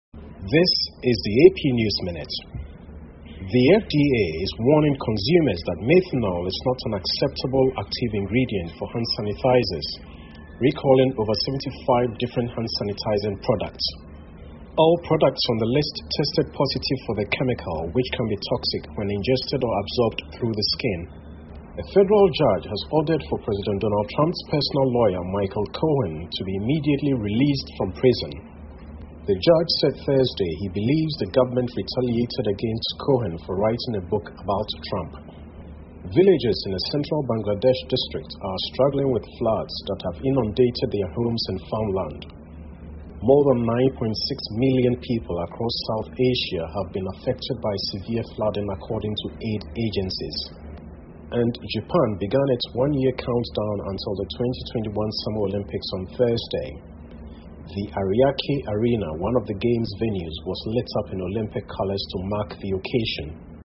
美联社新闻一分钟 AP 美国FDA召回75款有毒洗手液 听力文件下载—在线英语听力室